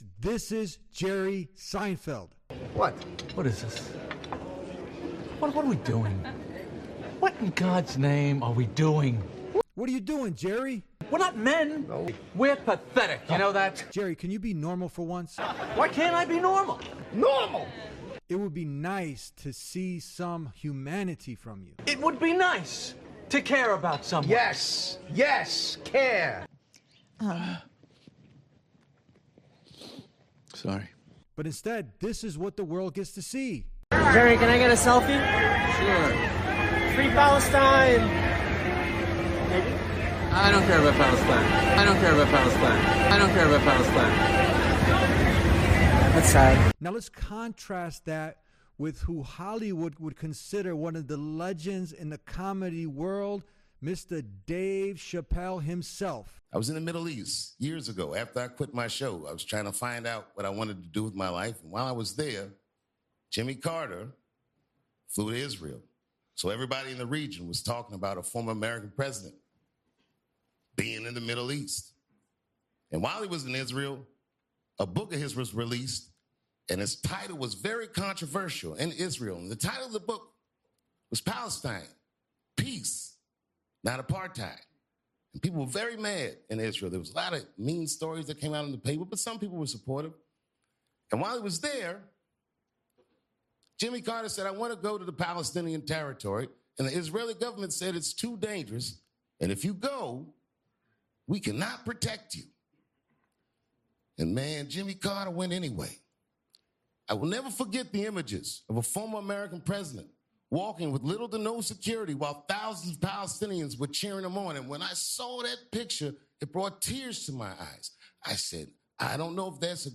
Episode REACTION February 19, 2025